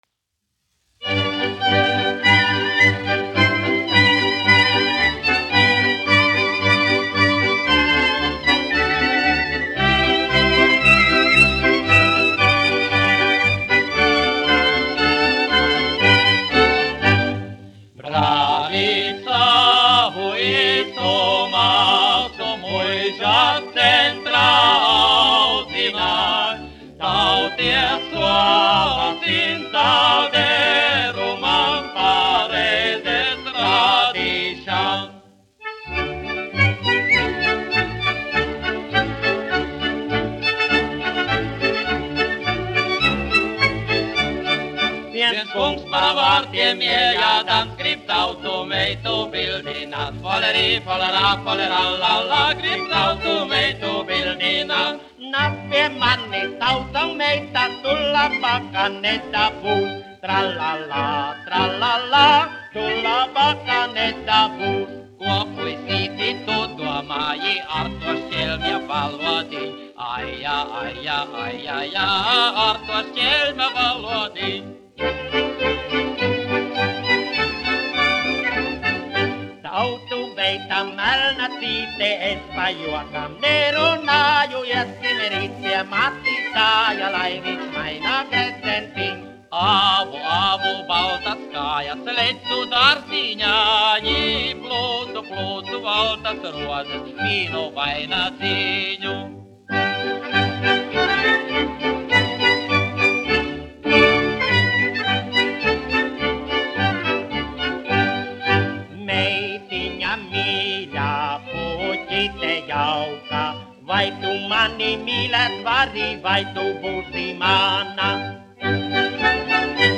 Brāļi Laivinieki (mūzikas grupa), izpildītājs
1 skpl. : analogs, 78 apgr/min, mono ; 25 cm
Latviešu tautasdziesmu aranžējumi
Marši
Skaņuplate
Latvijas vēsturiskie šellaka skaņuplašu ieraksti (Kolekcija)